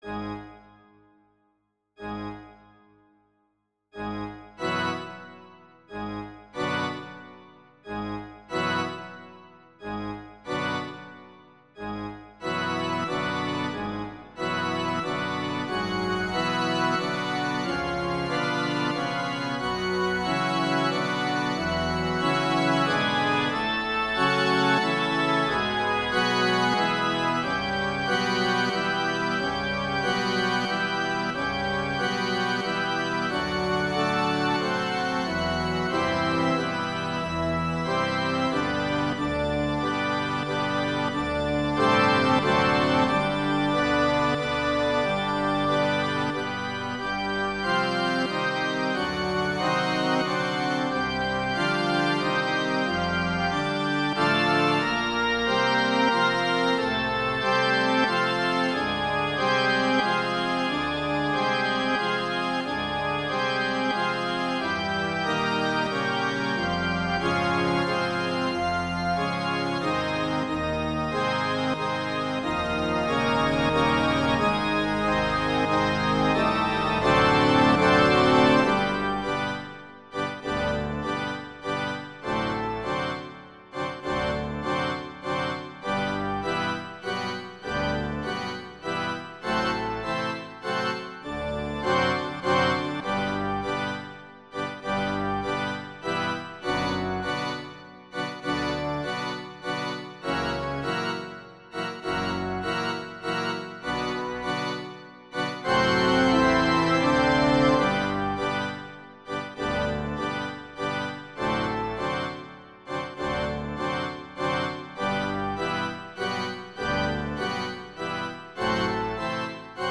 (Sad Waltz)
classical
E minor
♩=92 BPM